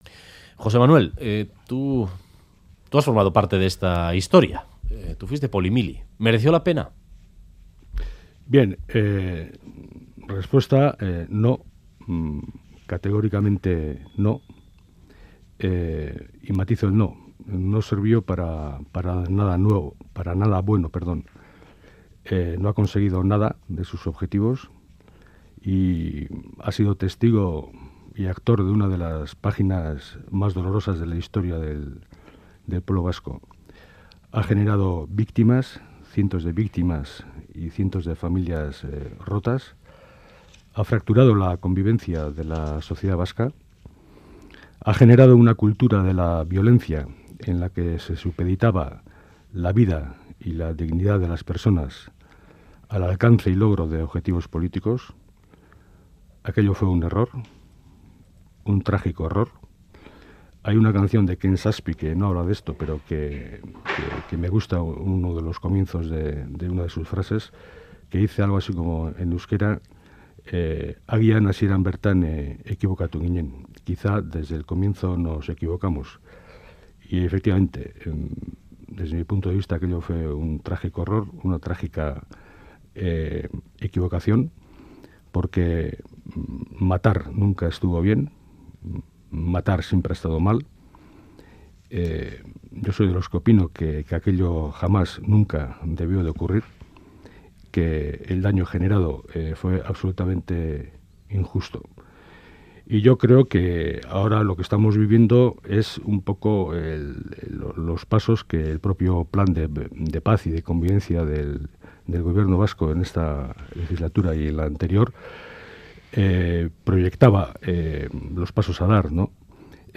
Audio: Entrevistado en 'Boulevard' responde a la pregunta de si aquello mereció la pena y señala que 'no, no sirvió para nada bueno, no ha conseguido nada de sus objetivos y ha generado víctimas'.